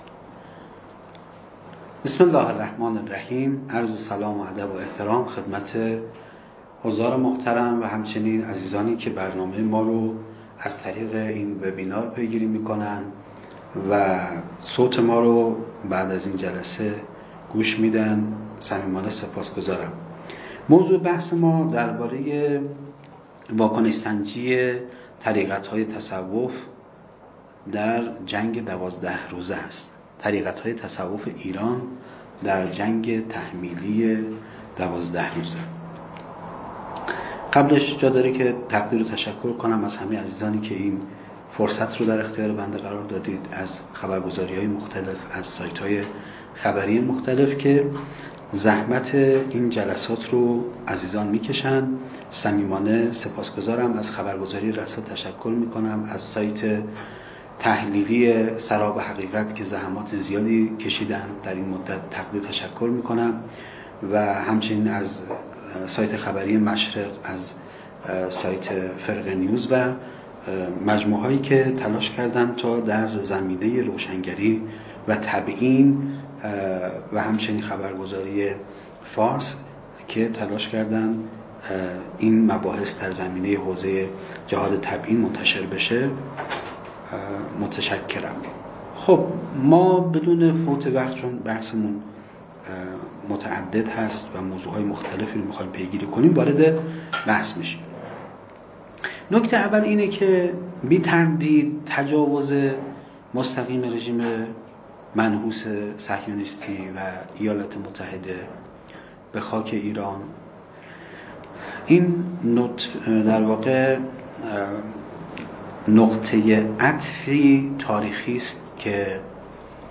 از ظرفیت‌های طریقت‌ها تا جریان‌های نفوذی در طریقت‌ها + صوت جلسه